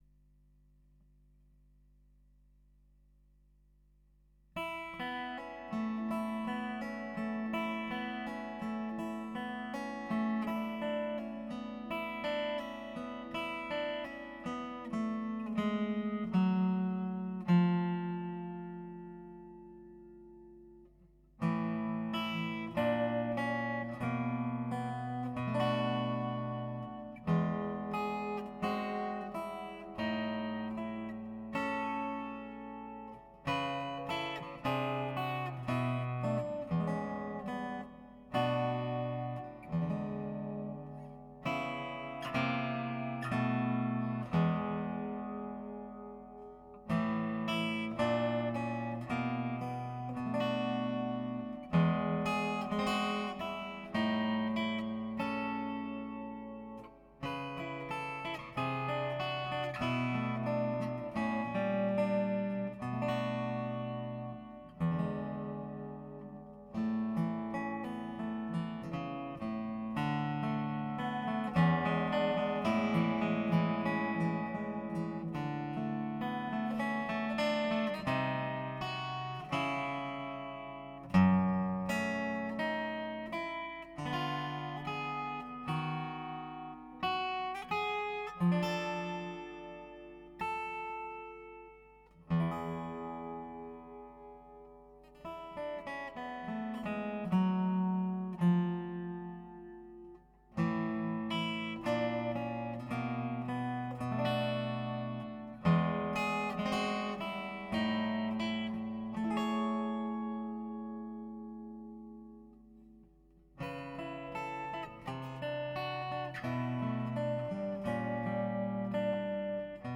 ドレッドノート用の、SHURE SM57のシミュレーションがありましたので、それを使っています。ブレンドは75％くらいです。曲が終わった後に、ブレンドを0%,50%,100%と比較した音も入れておきました。MagicStompAGのシミュレーションよりはマイク録音に近い感じですが、やっぱり本物のマイク録音とは箱鳴り感がかなり違いますね。